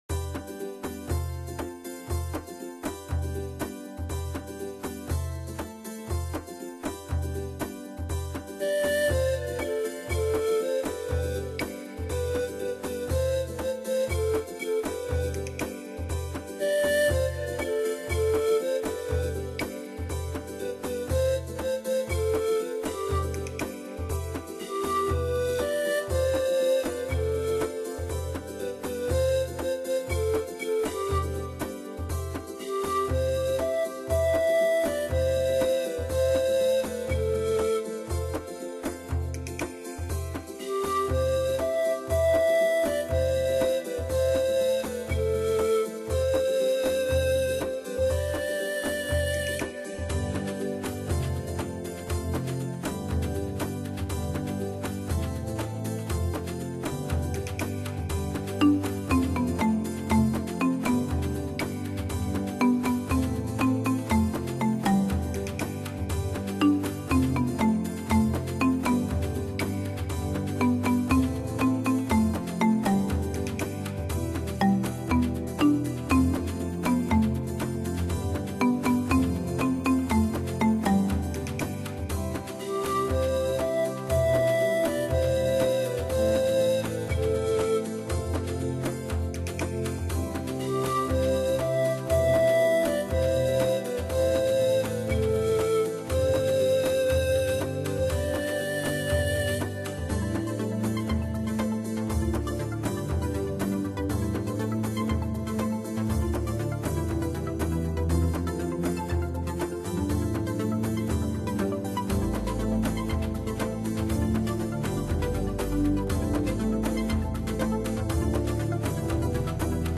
Genre: New Age
Instantly catchy, tuneful, and free of complexity